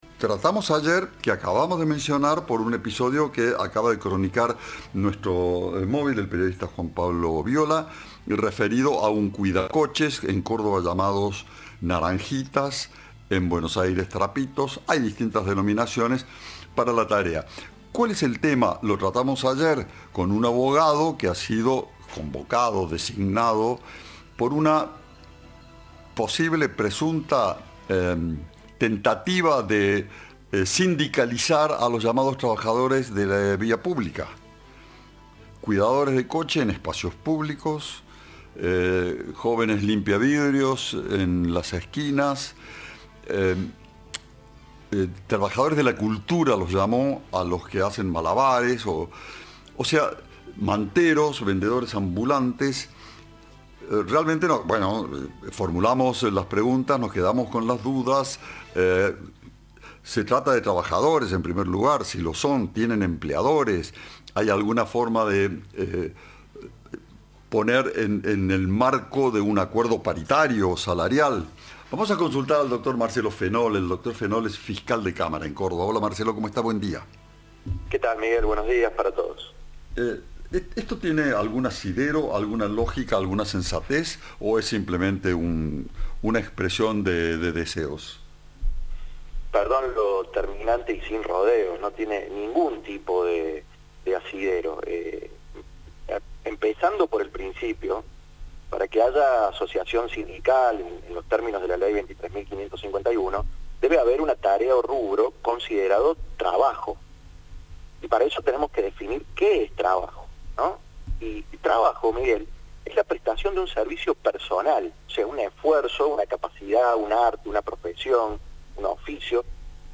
El fiscal cordobés, Marcelo Fenoll, dijo a Cadena 3 que esa idea “no tiene ningún tipo de asidero”.